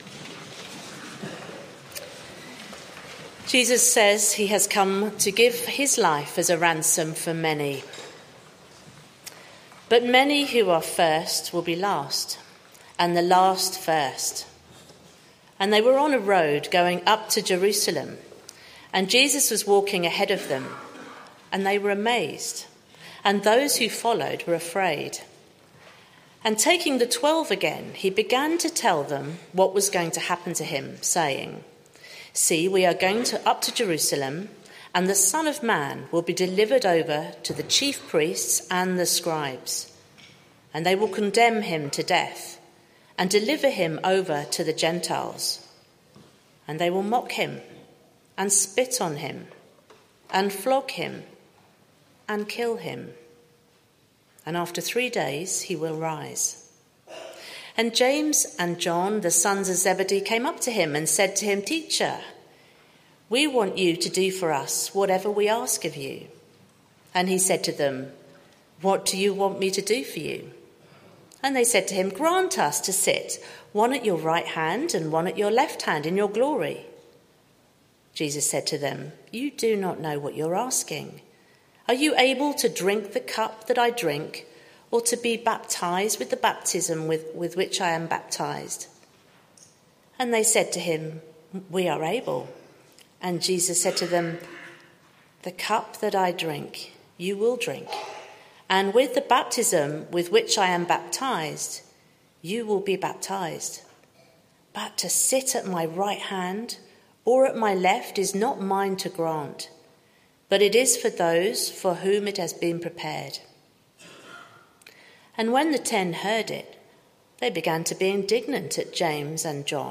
Why did Jesus come? Sermon - Audio Only Search media library...